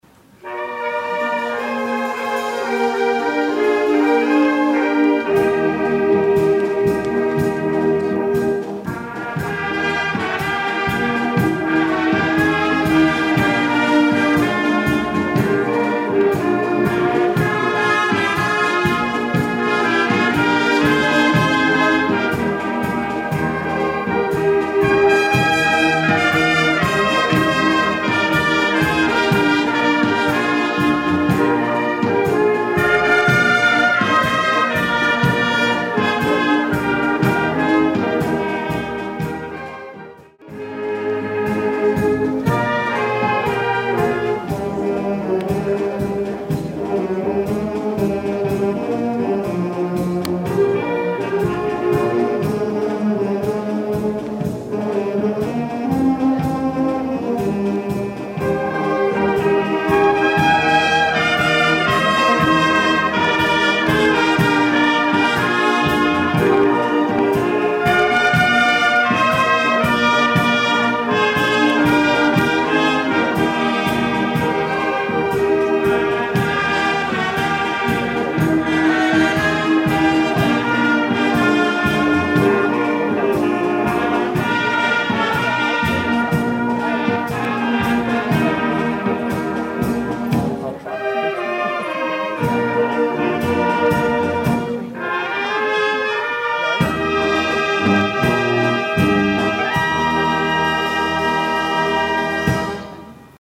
Trompetensolo